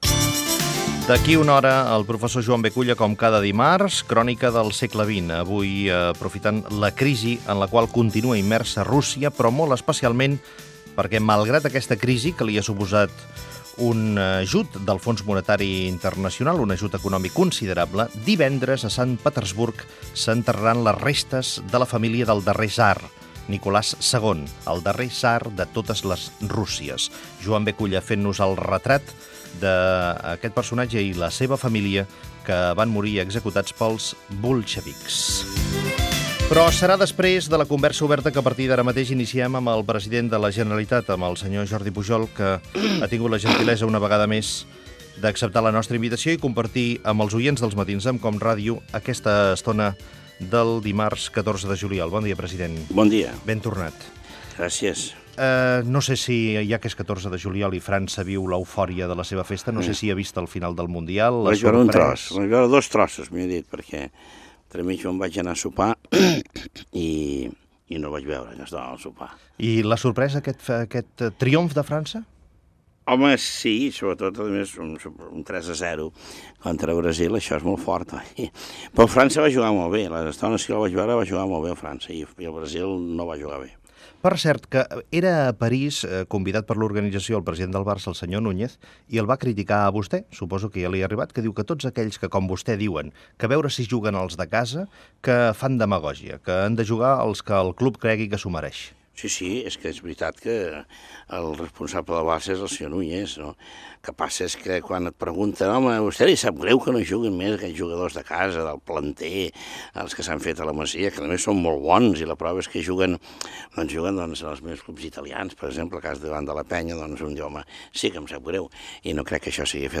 Fragment d'una entrevista al president de la Generalitat Jordi Pujol.
Info-entreteniment